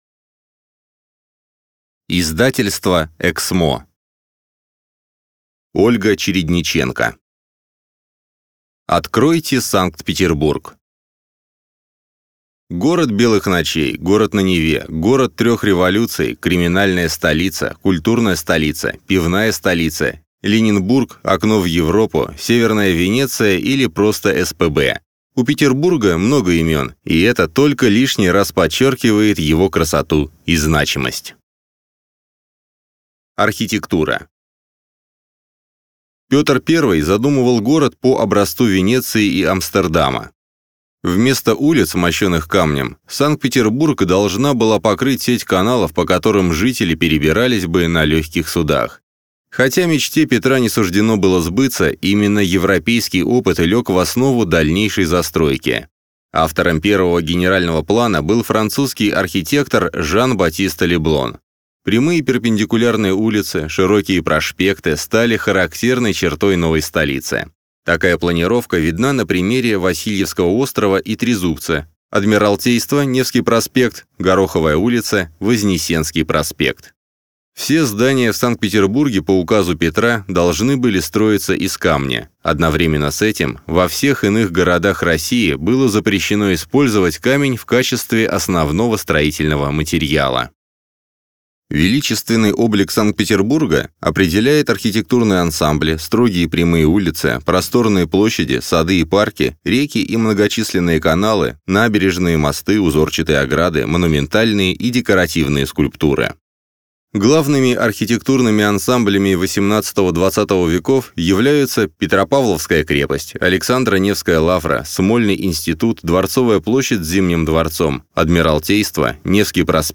Аудиокнига Санкт-Петербург. Путеводитель | Библиотека аудиокниг